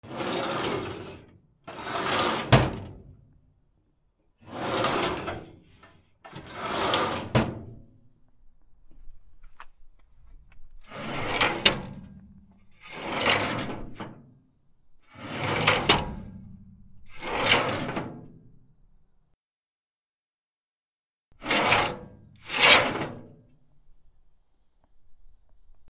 金属の引き出し
/ M｜他分類 / L01 ｜小道具 / 金属